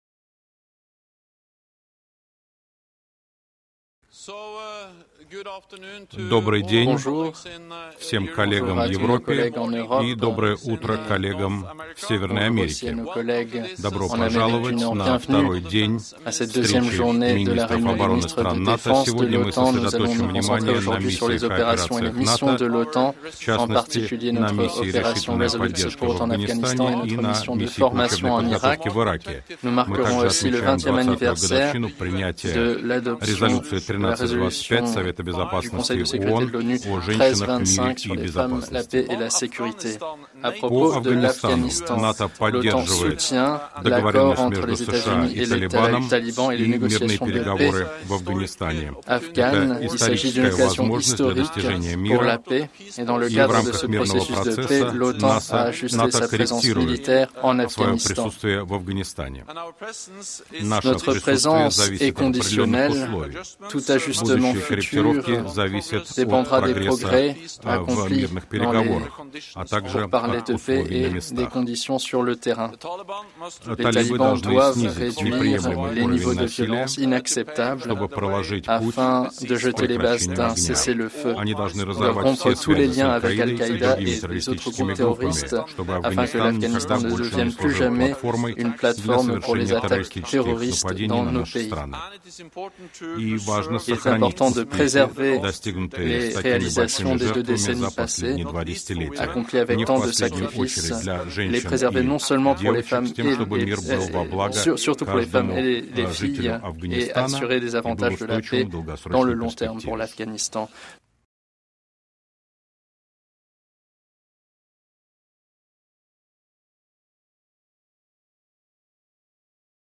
ENGLISH - Opening remarks by NATO Secretary General Jens Stoltenberg at the North Atlantic Council meeting at the level of Defence Ministers
Meeting of the North Atlantic Council via tele-conference